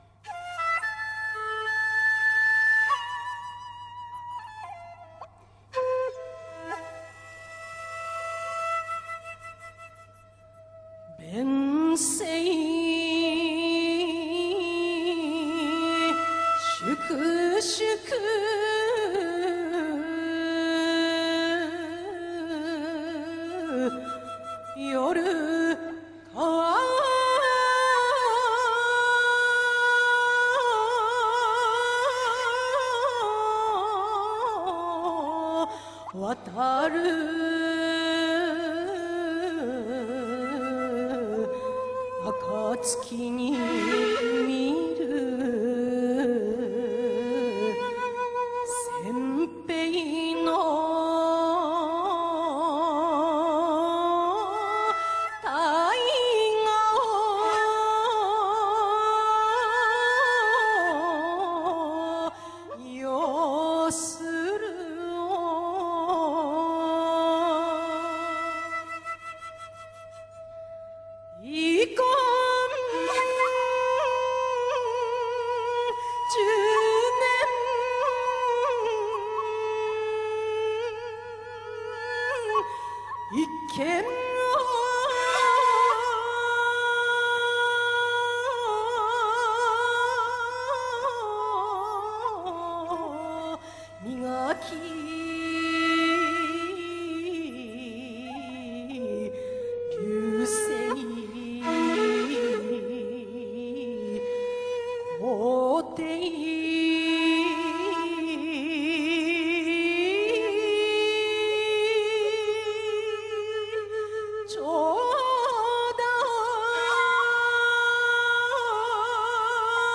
詩吟　「 川中島 」